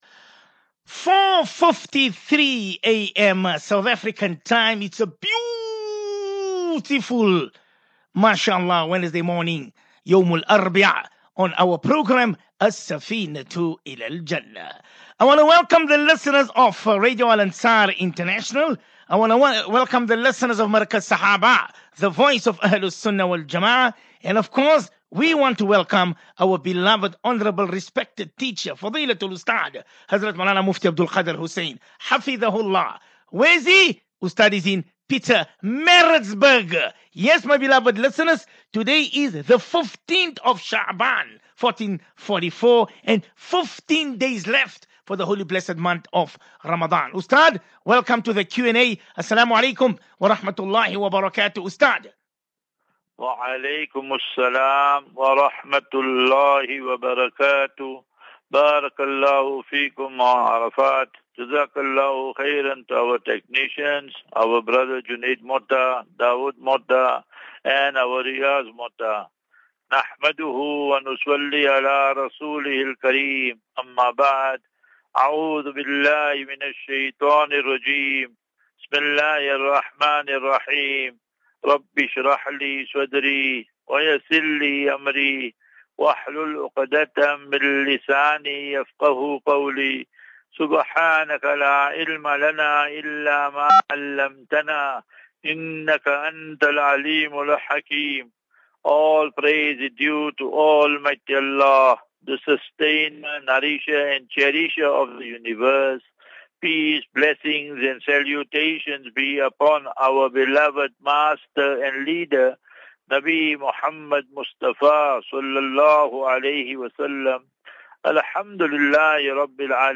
View Promo Continue Install As Safinatu Ilal Jannah Naseeha and Q and A 8 Mar 08 Mar 23 Assafinatu